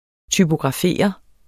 Udtale [ tybogʁɑˈfeˀʌ ]